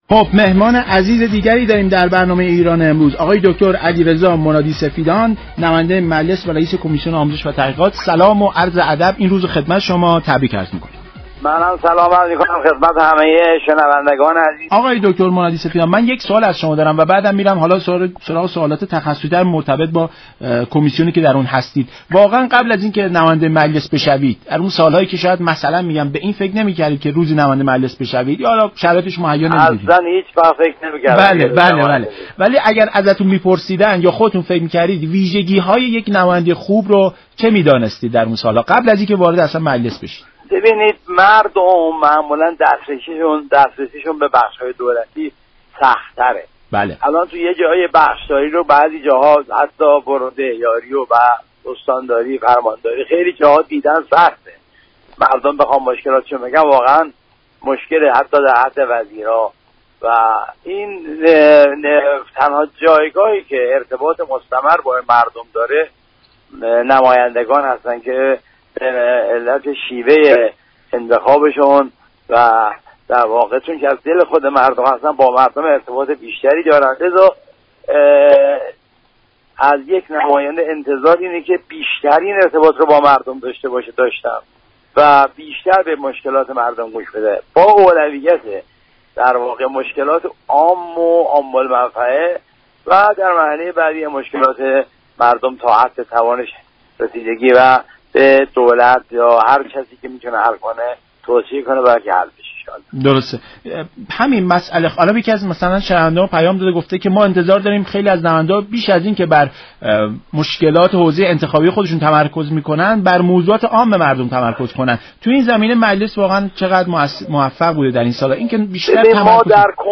رییس كمیسیون آموزش و تحقیقات در برنامه ایران‌امروز گفت:‌كمیسیون آموزش و تحقیقات مجلس با تصویب قانون رتبه‌بندی معلمان به مشكلات یك میلیون معلم رسیدگی كرد.